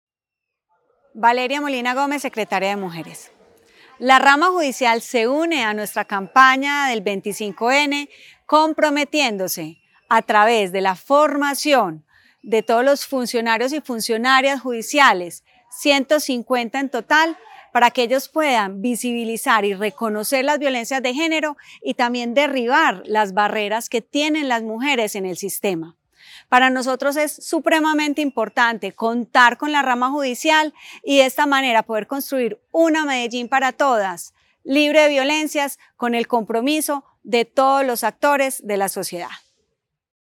Declaraciones de la secretaria de las Mujeres, Valeria Molina Gómez
Declaraciones-de-la-secretaria-de-las-Mujeres-Valeria-Molina-Gomez-1.mp3